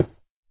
stone3.ogg